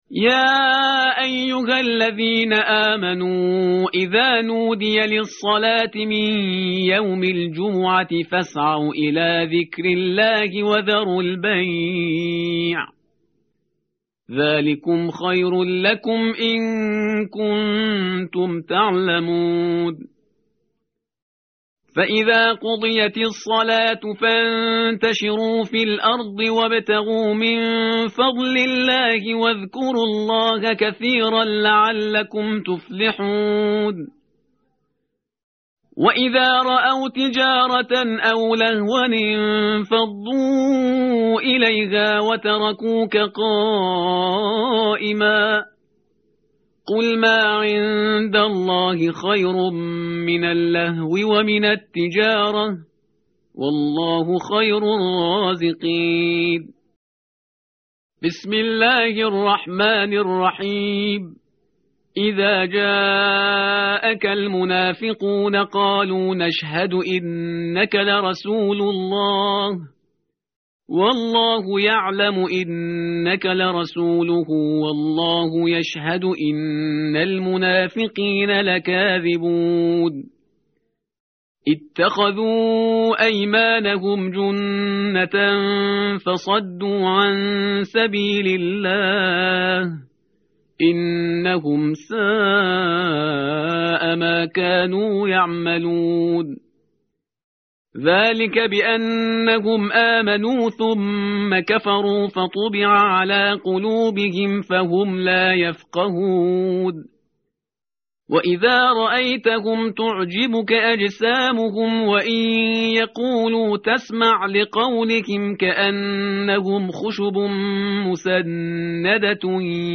متن قرآن همراه باتلاوت قرآن و ترجمه
tartil_parhizgar_page_554.mp3